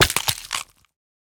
Minecraft Version Minecraft Version latest Latest Release | Latest Snapshot latest / assets / minecraft / sounds / mob / turtle / egg / egg_break2.ogg Compare With Compare With Latest Release | Latest Snapshot
egg_break2.ogg